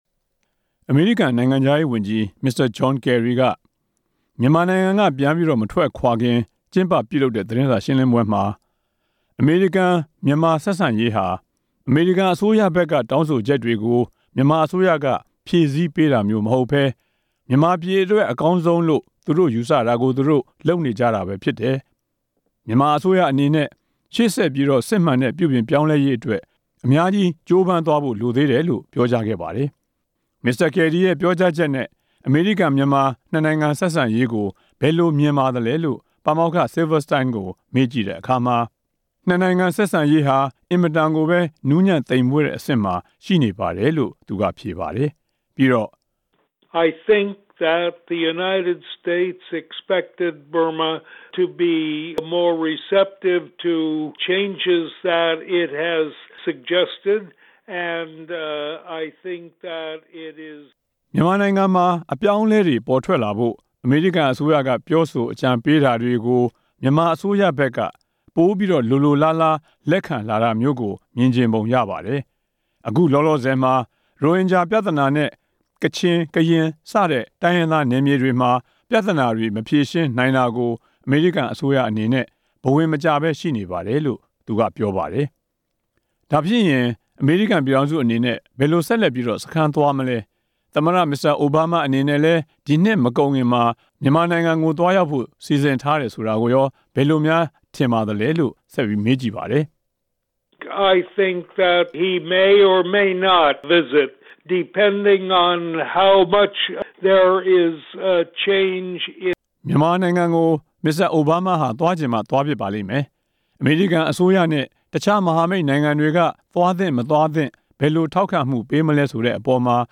မြန်မာ့အရေး နယူးယောက် ပါမောက္ခနဲ့ မေးမြန်းချက်